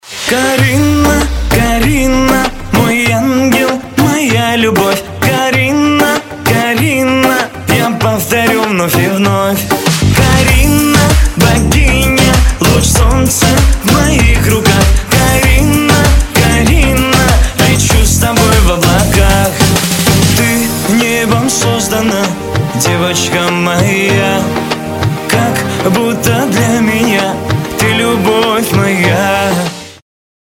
• Качество: 256, Stereo
поп
Кавсказские